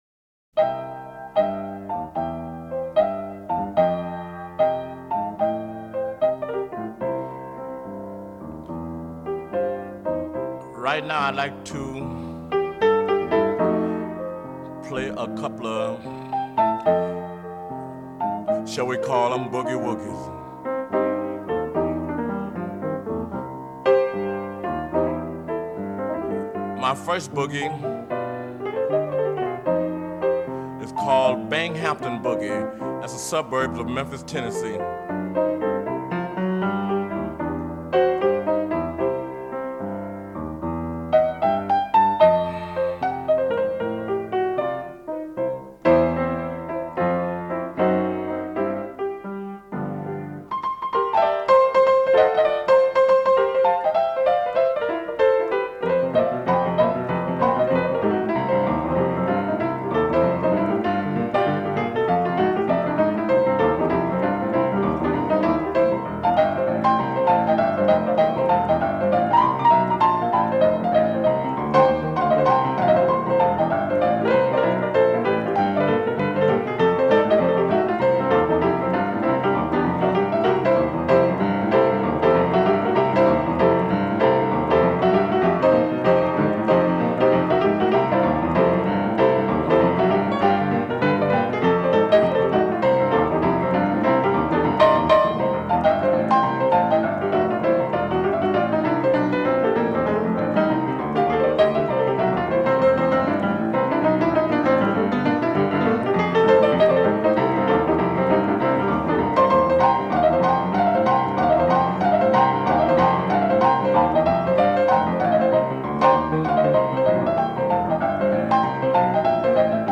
legendary Blues vocalist/pianist
released with fully restored artwork and remastered audio!